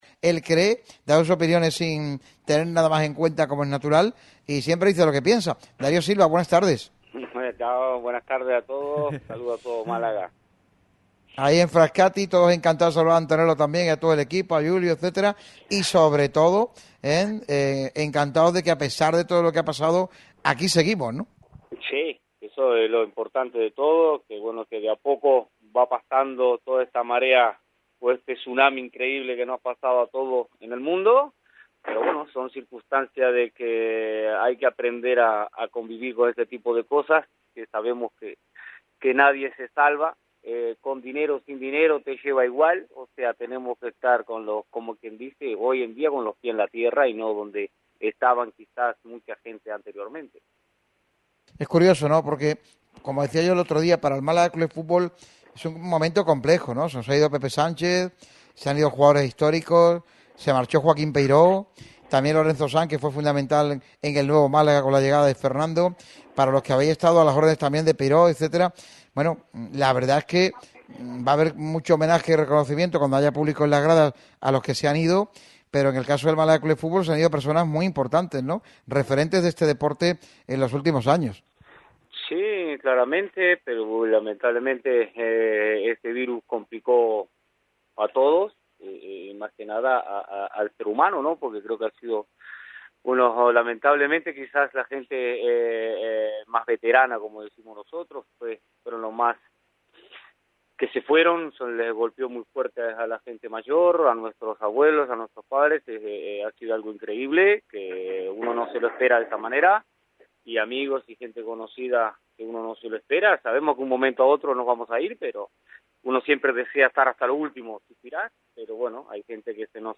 Darío Silva se pasó por la sintonía de Radio Marca Málaga para hablar de la vuelta al fútbol en España y la situación del Málaga Club de Fútbol, quien se juega la permanencia en los once partidos que restan de temporada.